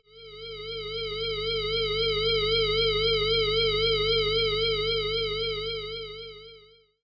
Guitar swell and noise » Guitar swell:solo
描述：Tokai strat, with delay, distortion, and a bit of verb, pod xt.
标签： bit Wav bmp Guitar Tremolo Swell Tokai 16 140 Noise Solo
声道立体声